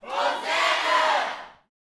UYC HOSANA.wav